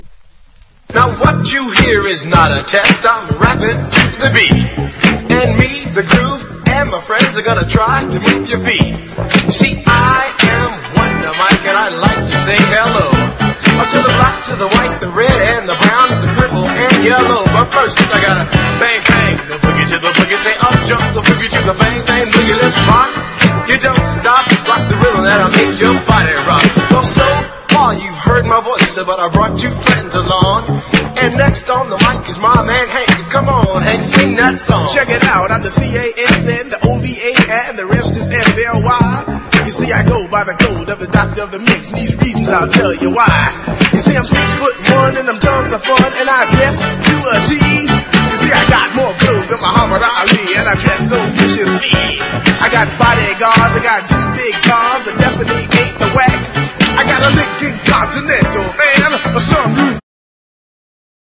HIP HOP/R&B / 00'S HIP HOP
メロウでポップ、インディー好きも聴いてほしいカナディアン・ヒップホップ05年作！
硬派なビート構築で魅せつつ、泣けてくるような切ないフロウが肝！